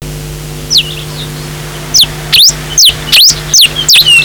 Donacospiza albifrons - Monterita cabeza gris